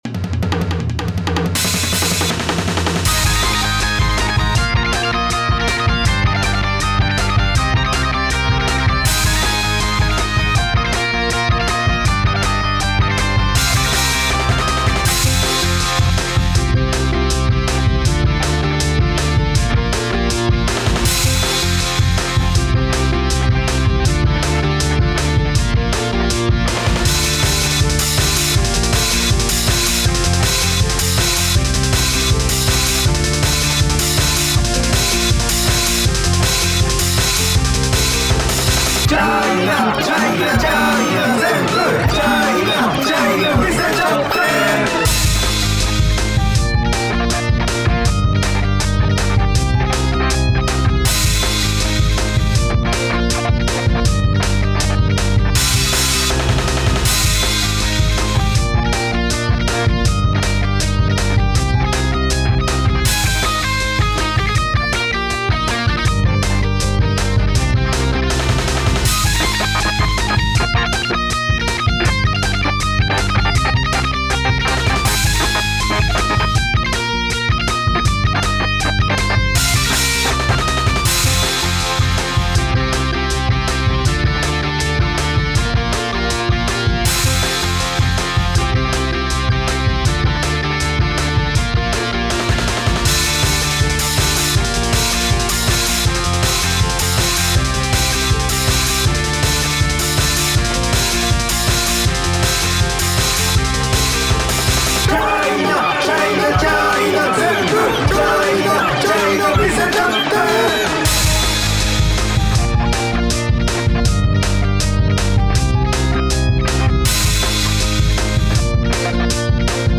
BPM：160